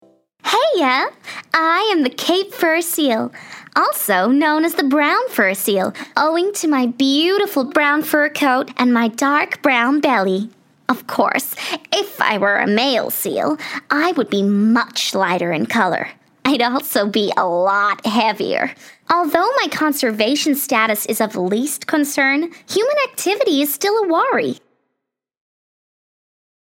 Sweet/motherly
My neutral South African, British and American accents lend themselves to easy listening.